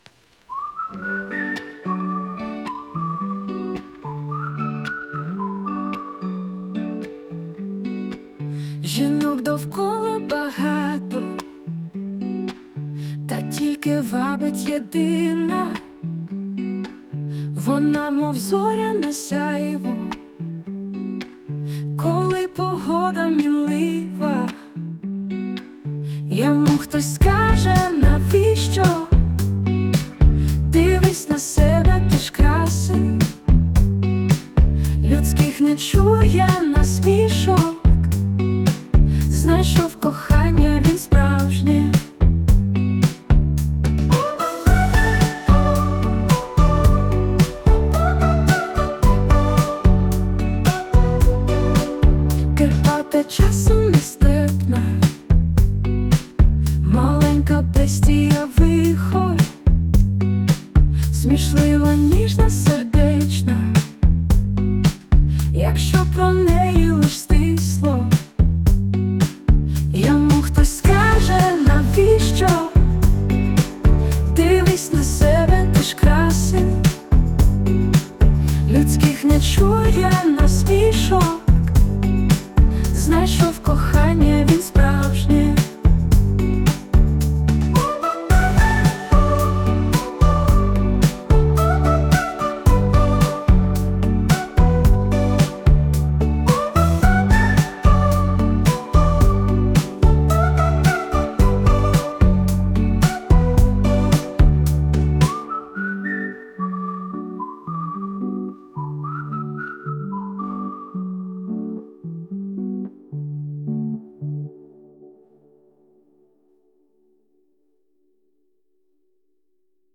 Музична композиція створена за допомогою SUNO AI
Яка музика файна вийшла!